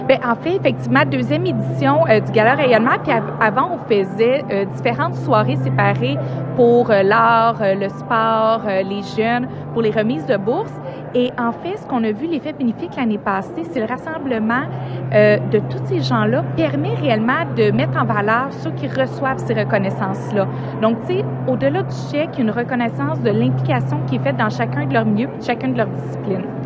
Julie Bourdon, mairesse de Granby.